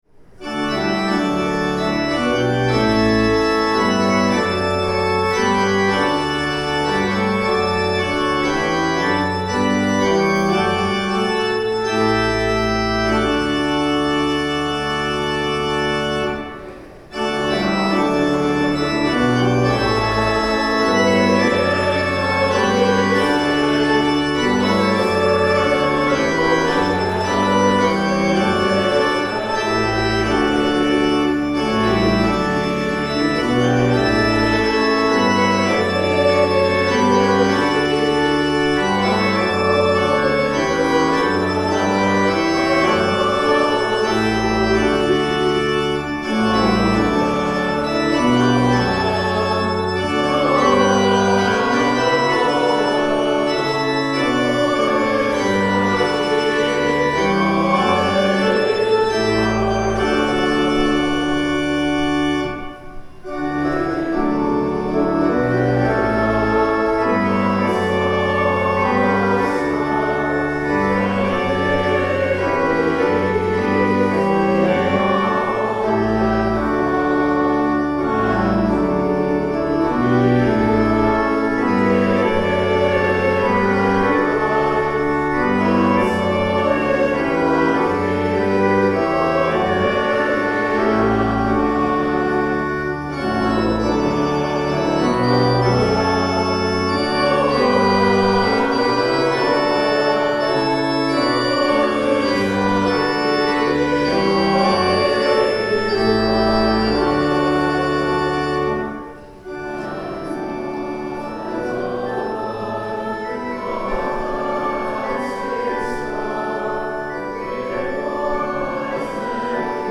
Hymn: The First Nowell (Common Praise #139)
The Lord’s Prayer (sung)
Hymn: From East to West (Common Praise #155)